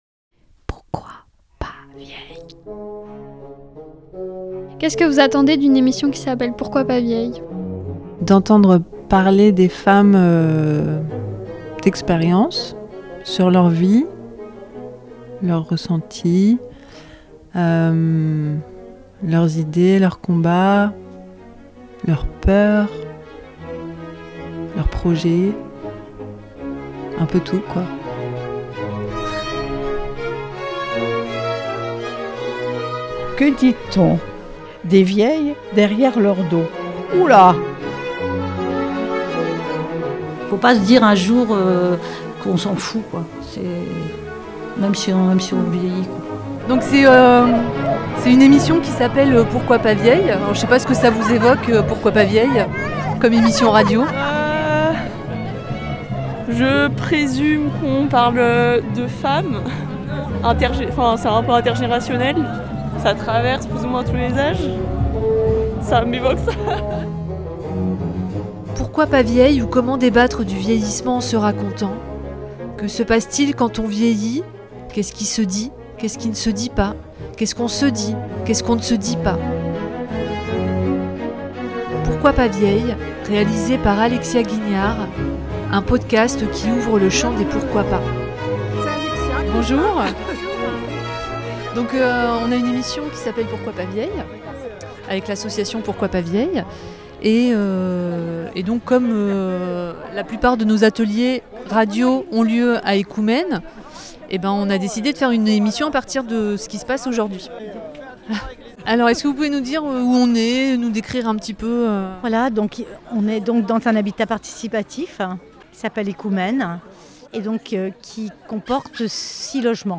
Cet épisode est réalisé à partir de voix de femmes, d'hommes, d'enfants collectées lors de la fête Du Bazzarr dans ma rue organisée par l'association Ekoumène à Brest. Une fête pour se rencontrer dans la rue, autour d'un lieu et d'un projet.